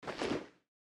equip_generic5.ogg